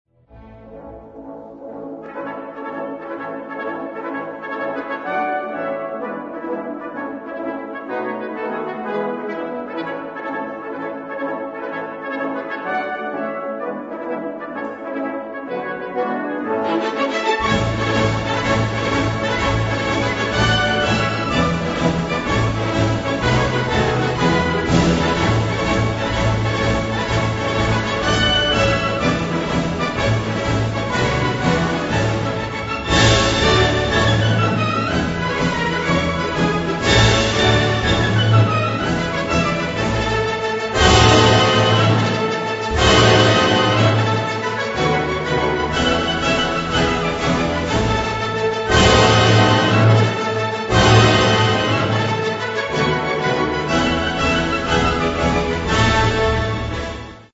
W łatwym układzie na keyboard